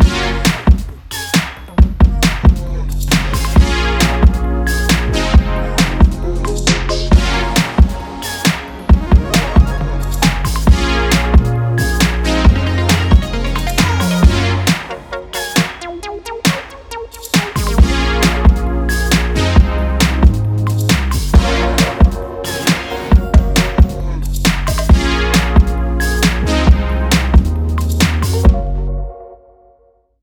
POSITIVE ELECTRO VIBE
Positive / Dynamic / Beauty / Retro